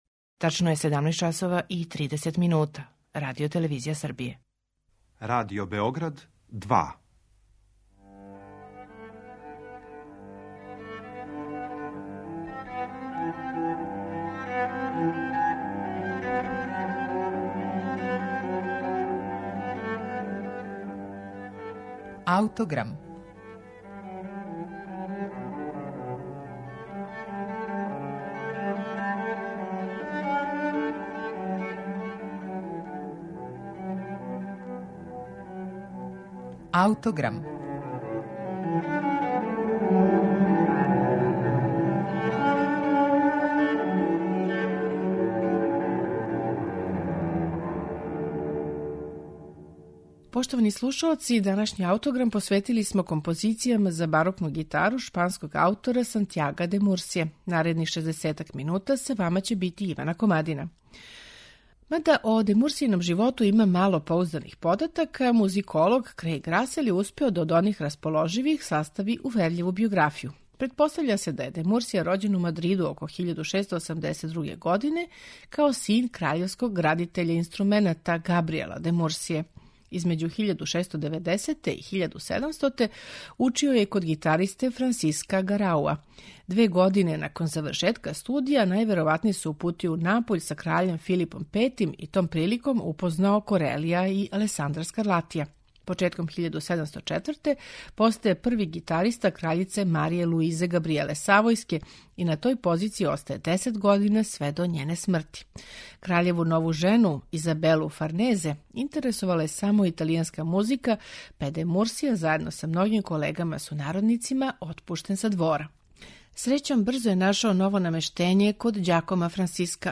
У данашњем Аутограму представићемо 14 Де Мурсијиних композиција за барокну гитару, у интерпретацији која оживљава начин на који су ова дела извођена у време свог настанка.
барокна гитара
харфа и псалтеријум
удараљке
барокне гитаре